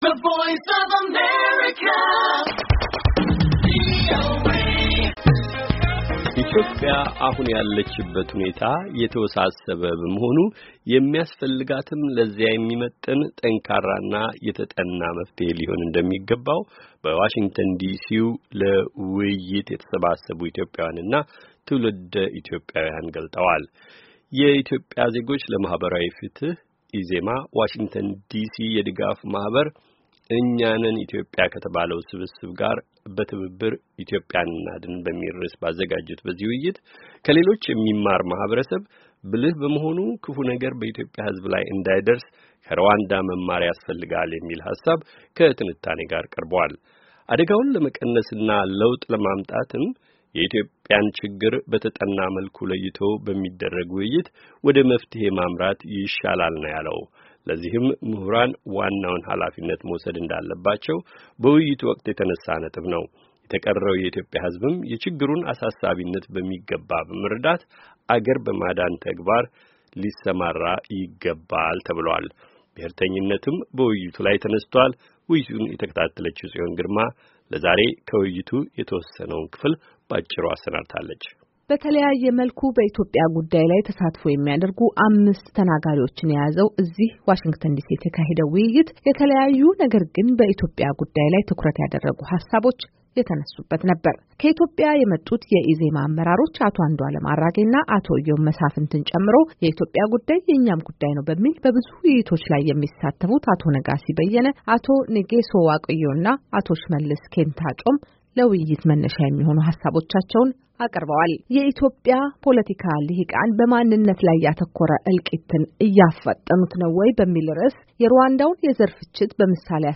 "ኢትዮጵያን እናድን" - ውይይት